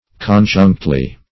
Search Result for " conjunctly" : The Collaborative International Dictionary of English v.0.48: Conjunctly \Con*junct"ly\, adv.
conjunctly.mp3